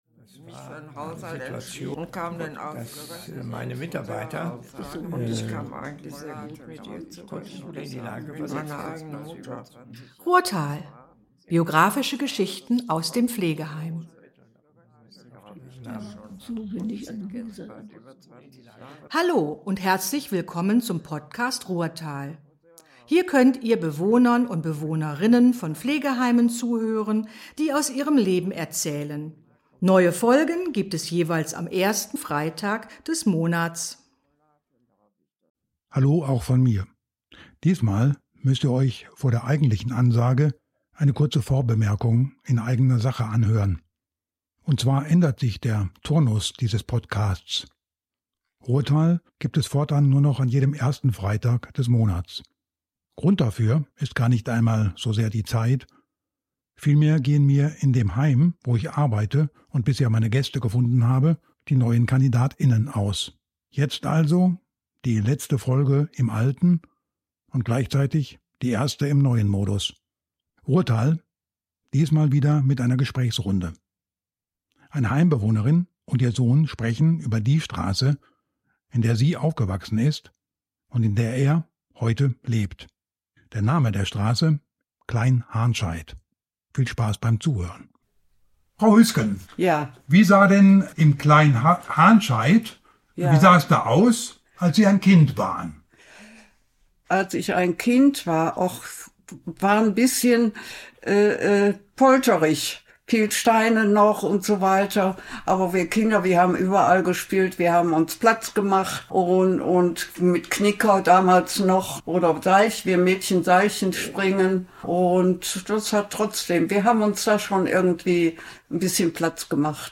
Eine Mutter und ihr Sohn berichten über eine Straße; sie erzählt, was sie als Kind dort erlebt hat; er berichtet davon, wie es sich heute dort lebt.